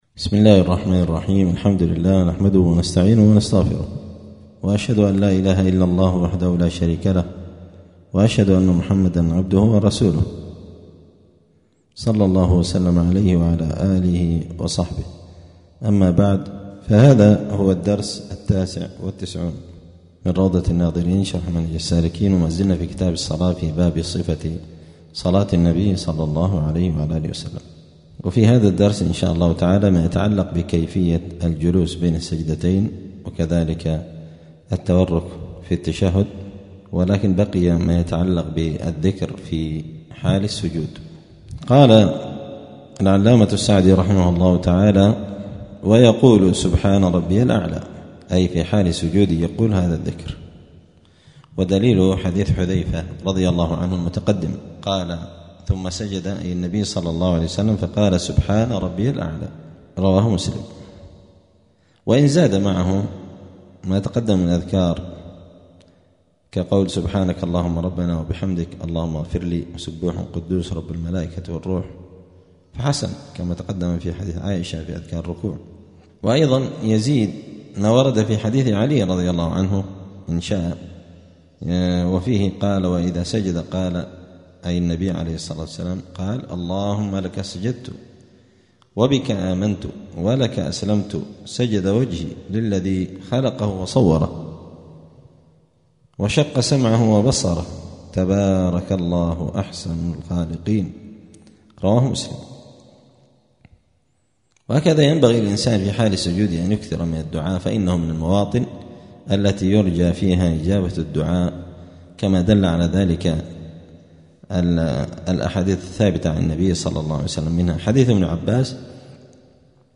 *الدرس التاسع والتسعون (99) {كتاب الصلاة باب صفة الصلاة كيفية الجلوس بين السجدتين والتورك للتشهد}*